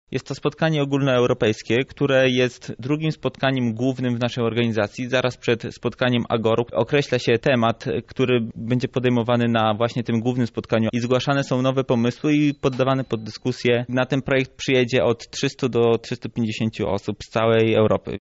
członek AEGEE